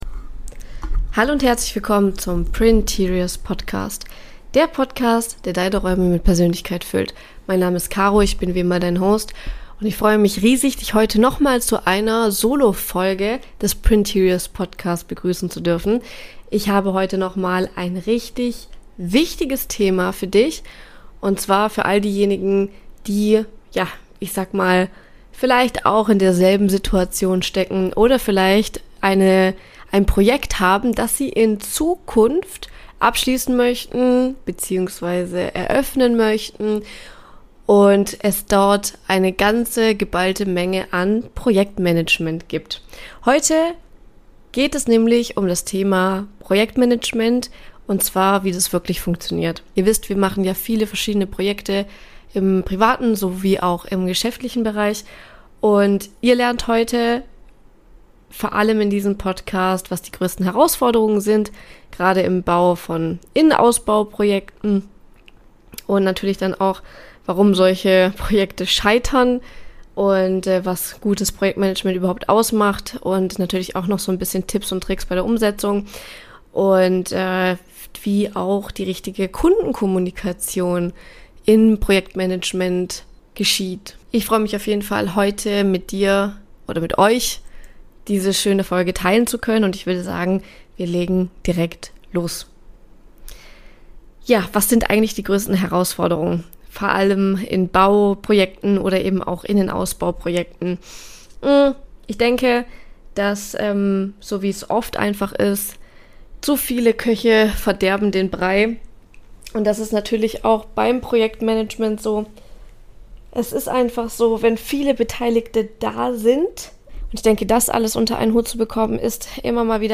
In dieser Solo-Episode des Printeriors Podcasts spreche ich darüber, warum Projekte im Bau- und Interior-Bereich so häufig aus dem Ruder laufen und was gutes Projektmanagement in der Praxis wirklich bedeutet.